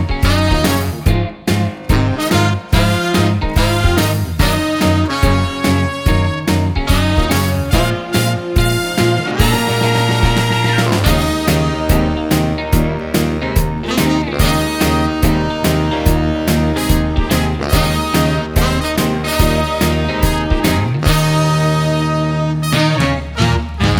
Soundtracks